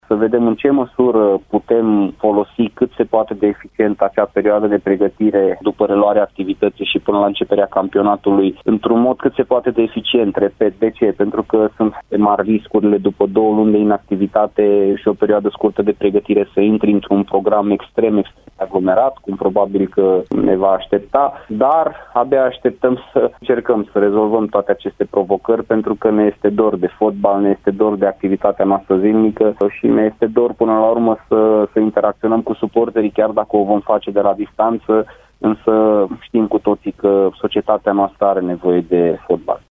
Cei doi tehnicieni, invitați azi la Arena Radio, au vorbit și despre stările de spirit ale loturilor pe care le conduc de la distanță: